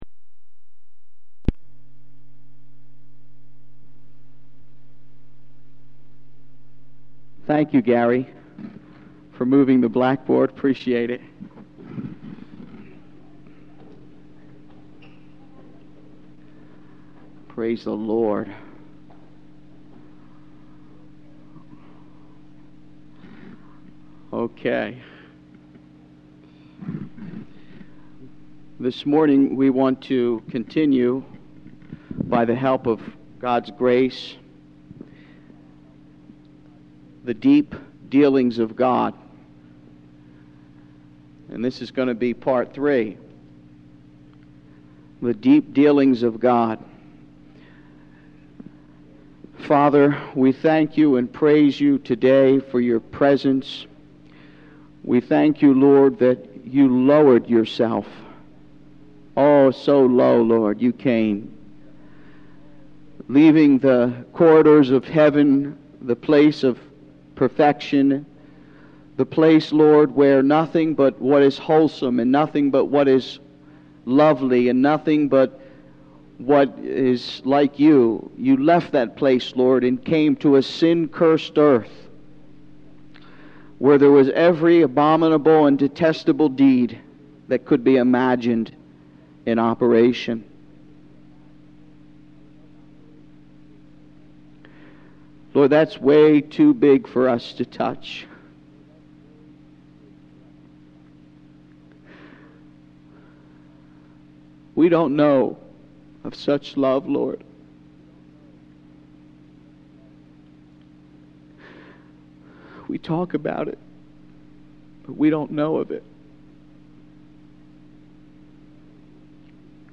Ultimately, the sermon calls for a commitment to seek God above all else, allowing Him to establish His lordship